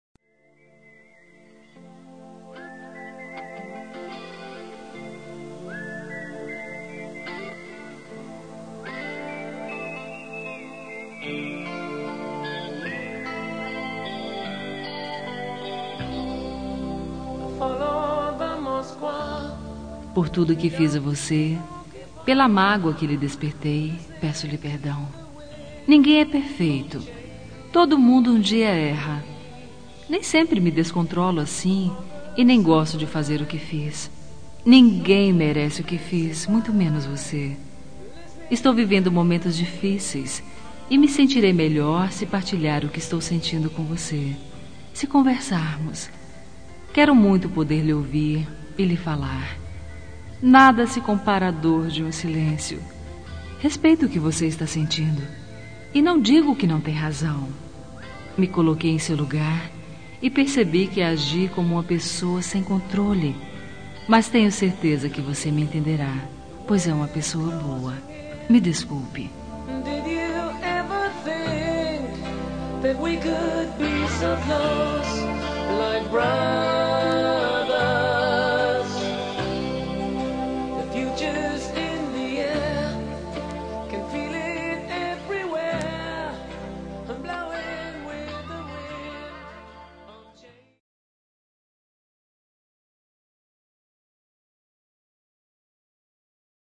Telemensagem de Desculpas – Voz Feminina – Cód: 345